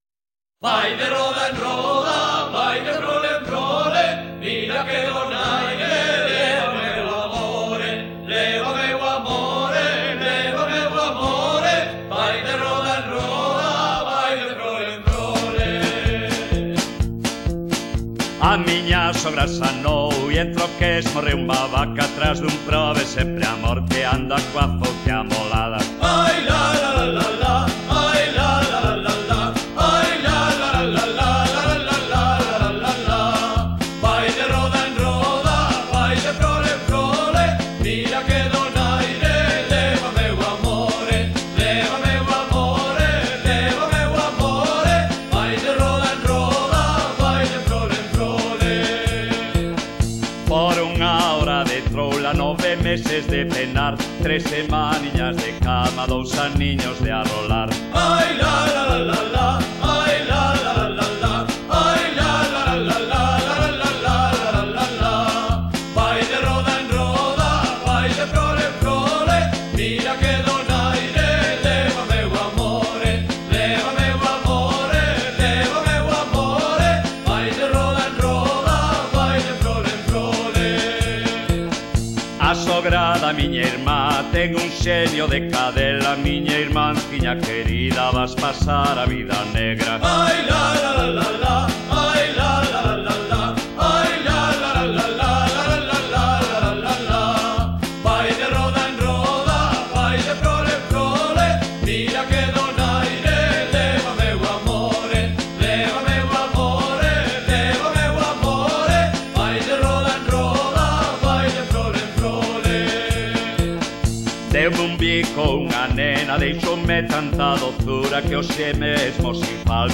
Letra: Popular
Música:Popular